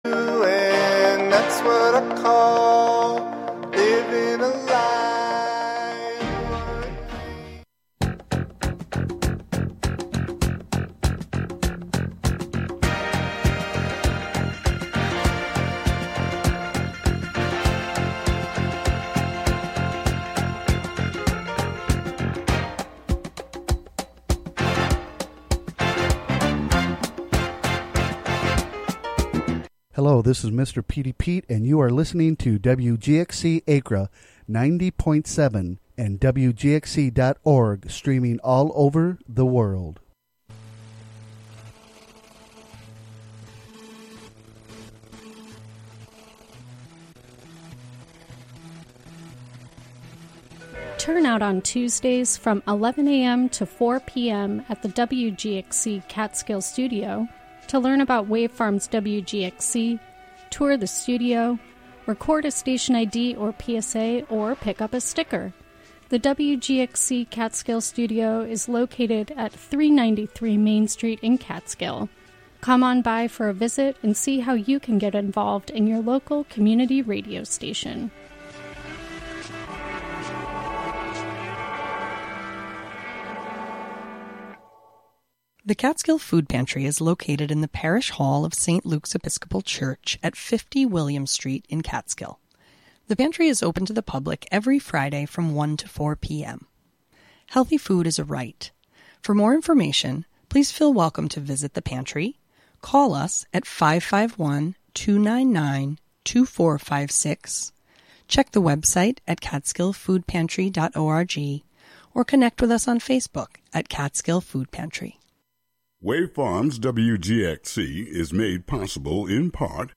Get yr weekly dose of music appreciation, wordsmithing, and community journalism filtered through the minds and voices of the Youth Clubhouses of Columbia-Greene, broadcasting live out of the Catskill Clubhouse on Fridays at 6 p.m. and rebroadcast Sundays at 7 a.m.